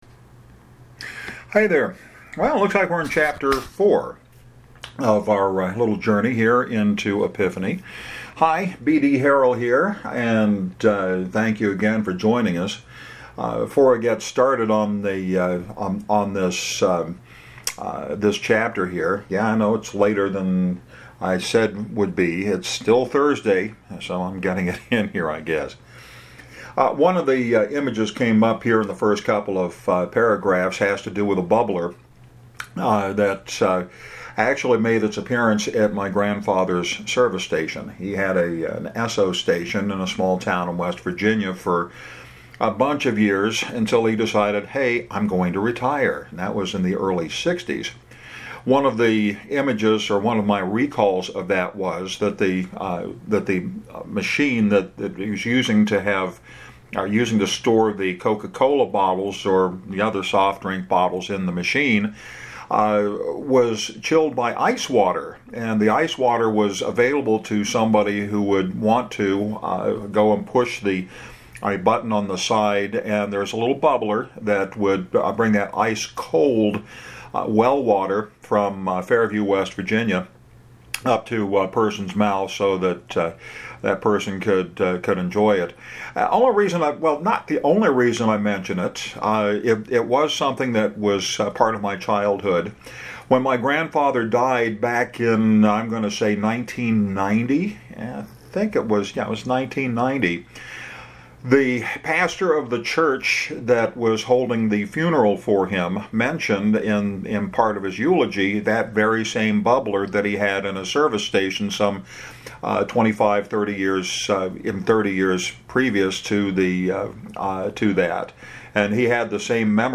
Actually, this week I continue the process of reading from Epiphany. Short chapter with Dan preparing to take a trip by himself. The fire at the Community Church is still heavy on his mind.